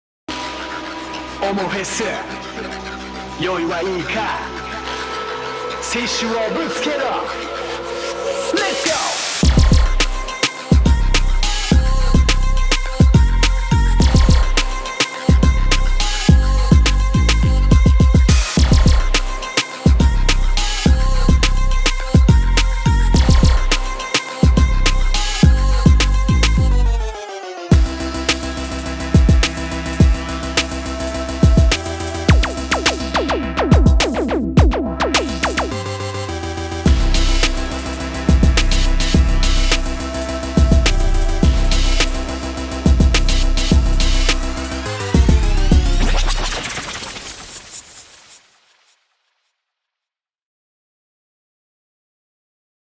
OMOFES_BPM105
OMOFES_BPM105.wav